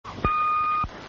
I took the short beeping sound. That serves as my message notification.
swedishbeep.mp3